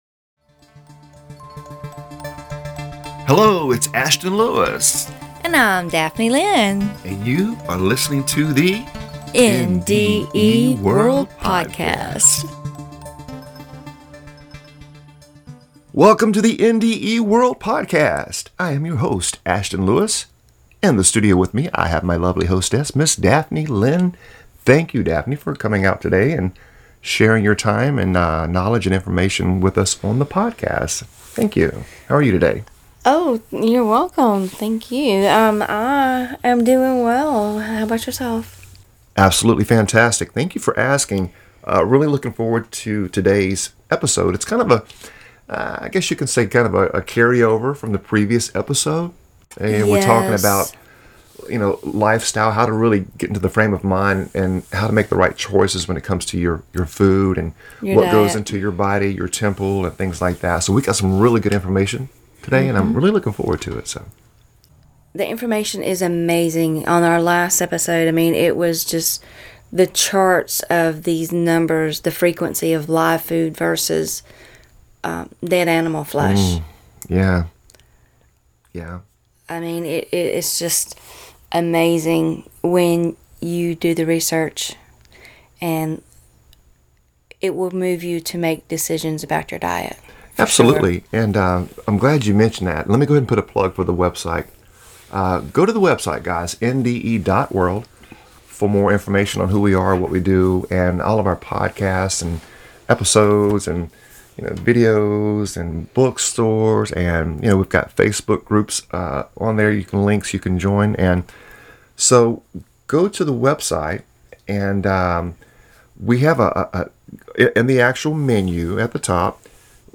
In this series, we will share the world of UDO pronounced (yoo' dō), with our listeners. UDO is the acronym for Universal Divine Oneness, and we will help you to reach that state of UDO.